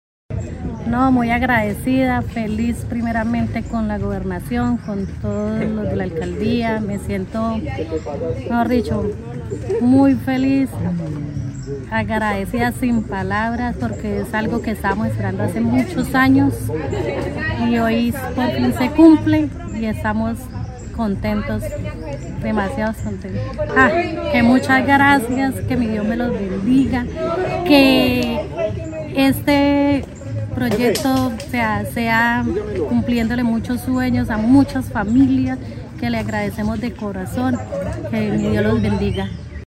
beneficiaria del proyecto.